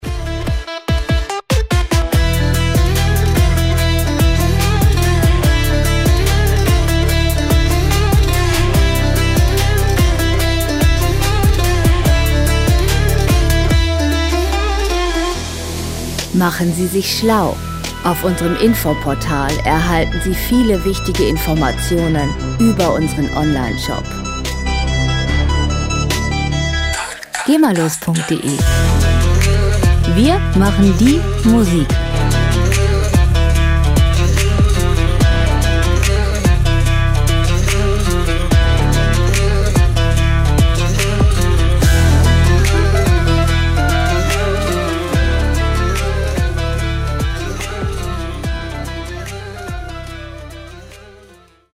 World Pop Music aus der Rubrik "Weltenbummler"
Musikstil: Hip-Hop
Tempo: 145 bpm
Tonart: E-Moll
Charakter: facettenreich, orientalisch
Instrumentierung: Saz, Klarinette, Vocals, Synthesizer